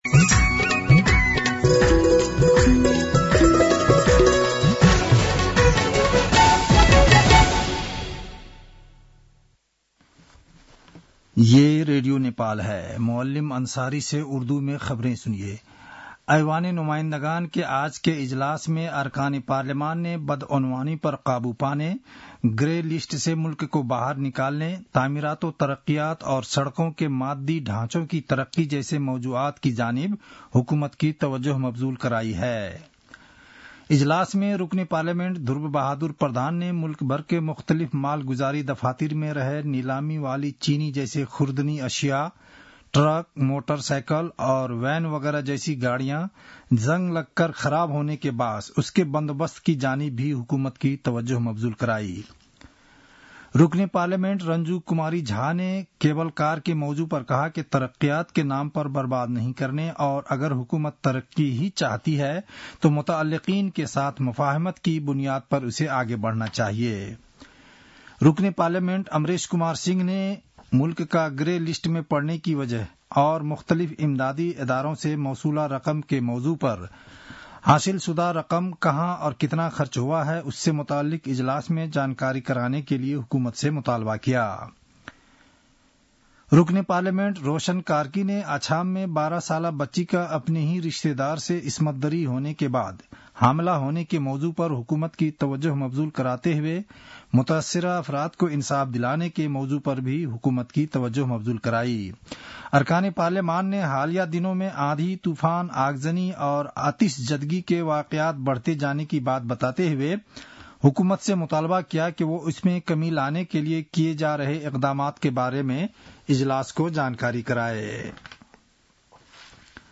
उर्दु भाषामा समाचार : १३ फागुन , २०८१
Urdu-news-11-12.mp3